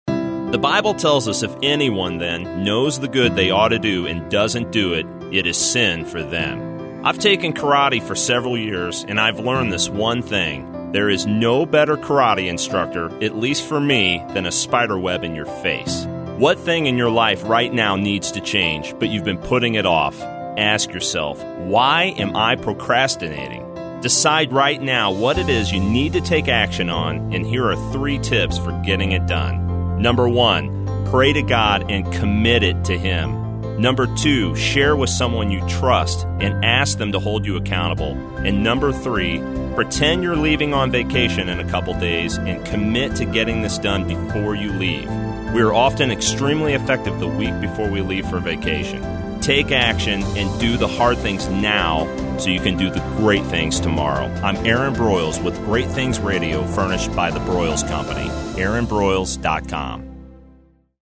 I’m excited to introduce Great Things Radio (1 min motivational messages) airing now on Bott Radio Network on 91.5 FM in St. Louis at approximately 5:35 p.m. CST (top of the second break in the Bible Answer Man Hank Hanegraaff broadcast).